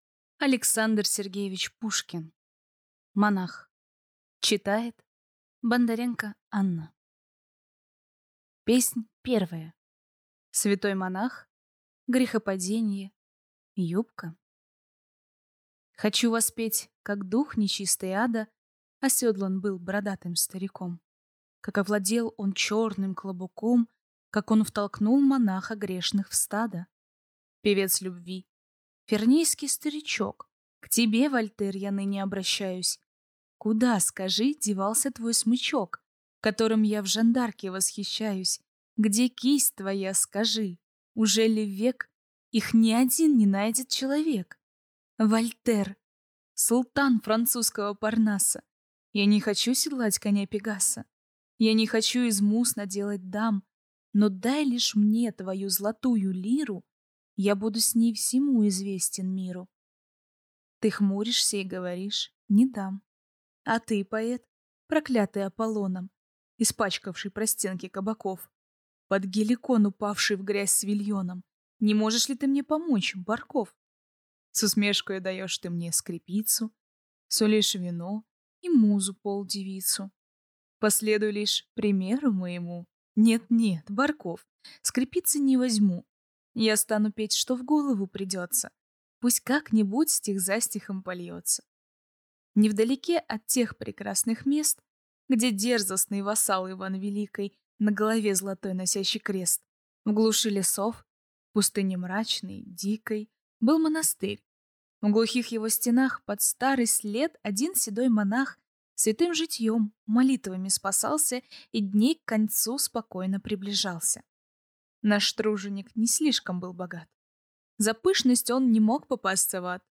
Аудиокнига Монах | Библиотека аудиокниг